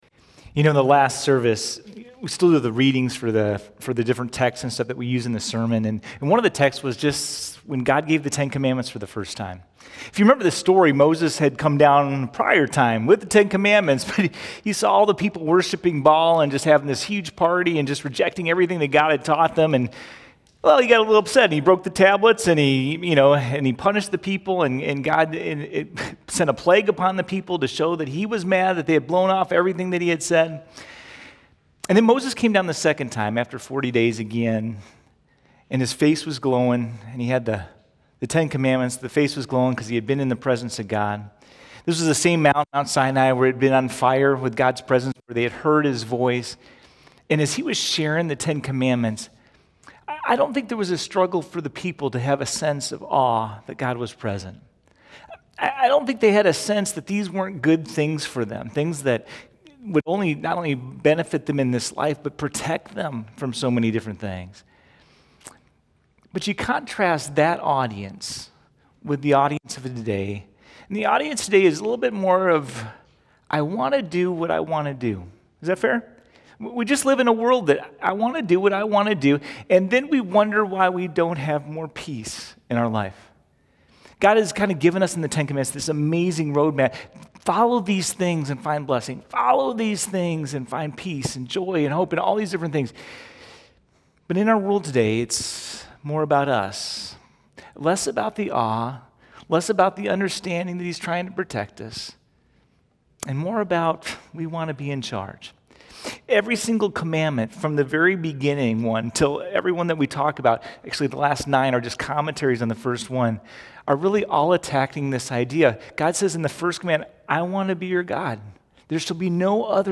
829Sermon-1.mp3